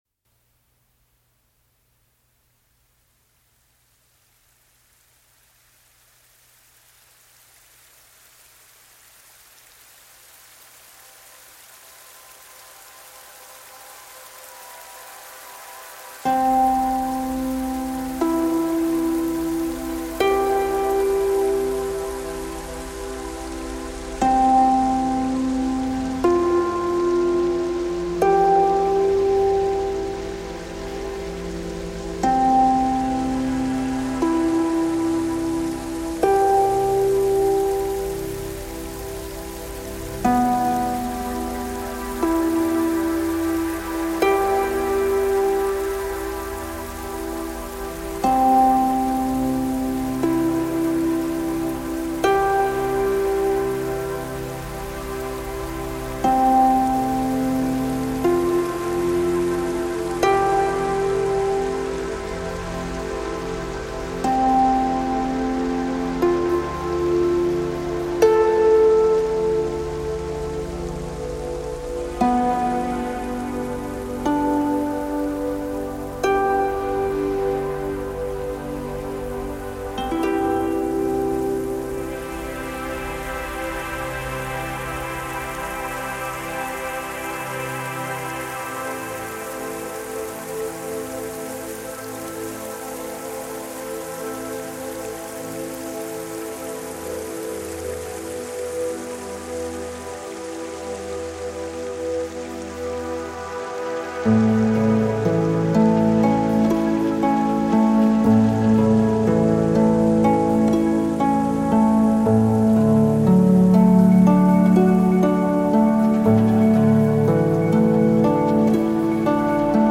Os exercícios musicais complementam a atividade da criança.